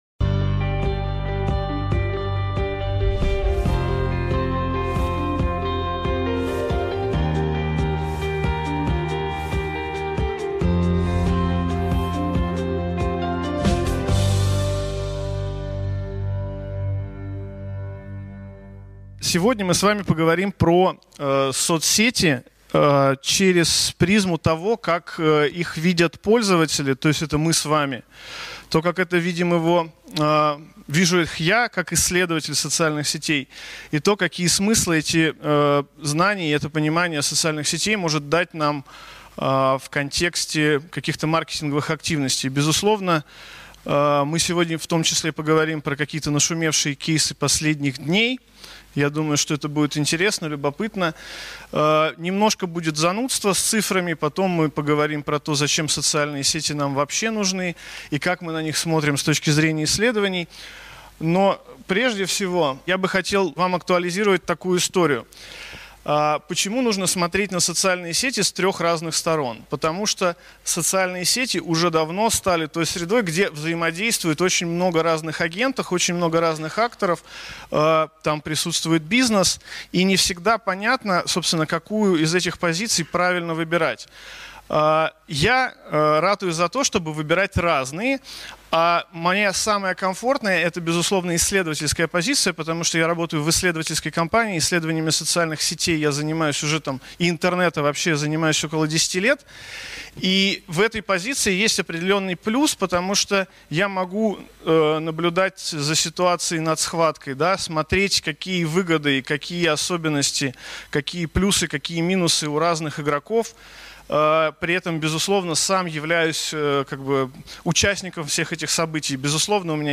Аудиокнига Социальные сети глазами пользователя, маркетолога и исследователя | Библиотека аудиокниг